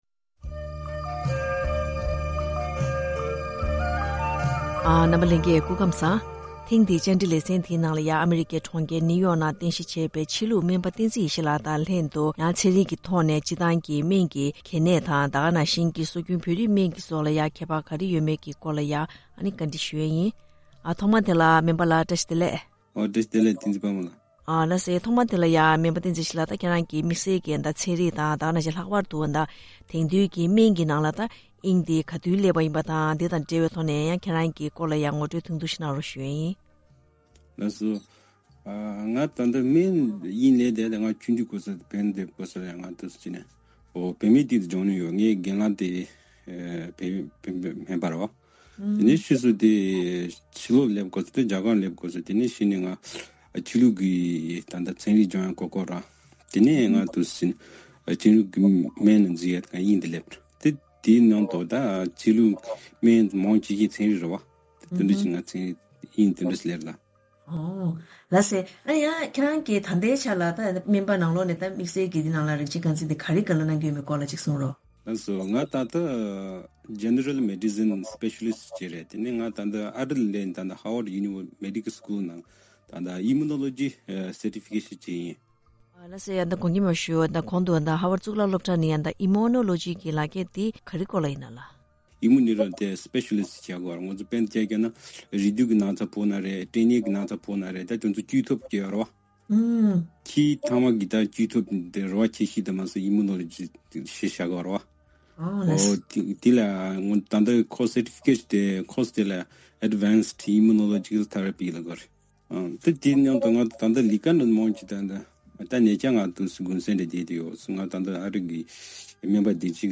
བཅའ་འདྲི་ཕྱོགས་བསྒྲིགས་ཞུས་པའི་ལས་རིམ།